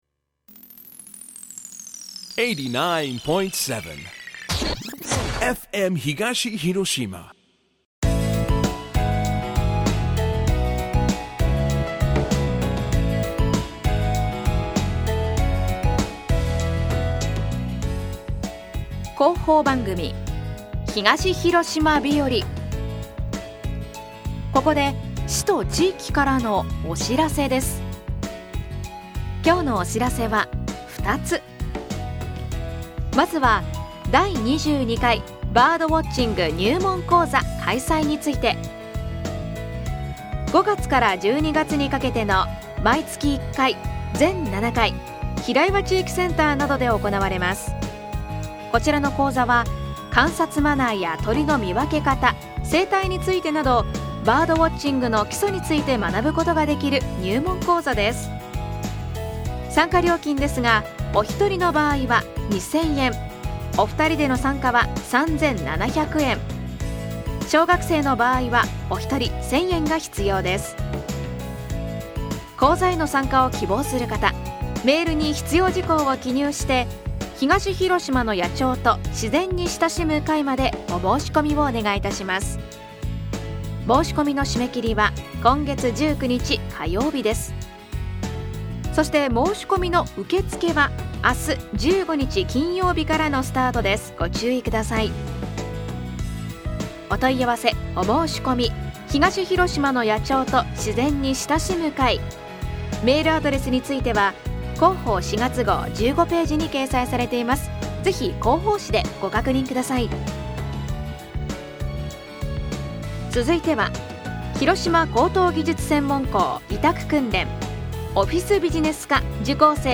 2022年４月14日ＦＭ東広島で放送した 広報番組「東広島日和」です。